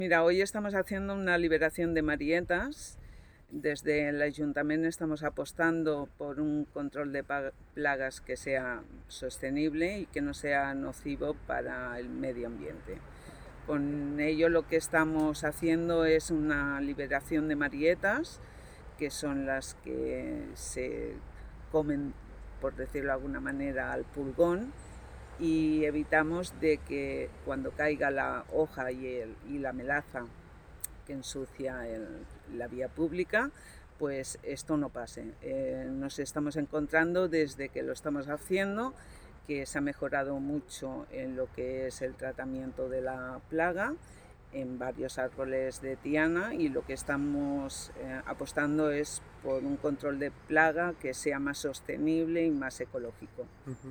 Amado explica que han apostat per un control de plagues sostenible i que no sigui nociu pel medi ambient: